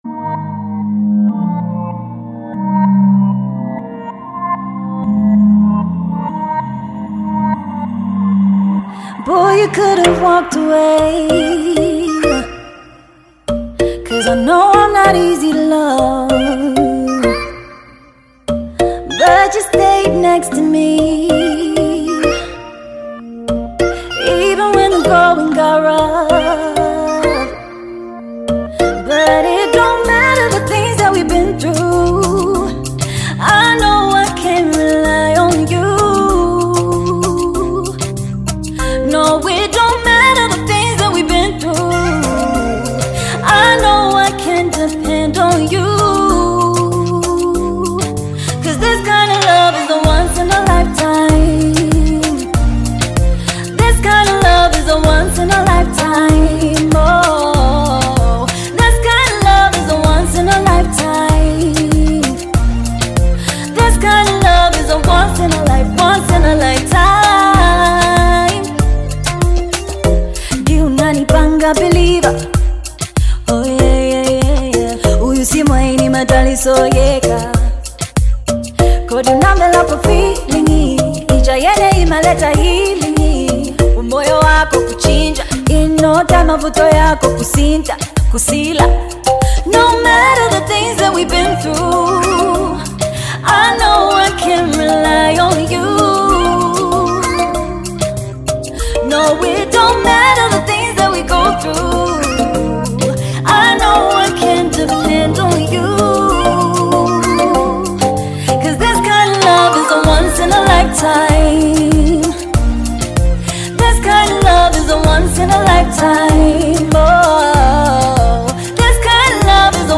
Zambian Afro-pop singer
is a mid-tempo Afro-pop track